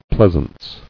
[pleas·ance]